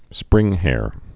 (sprĭnghâr)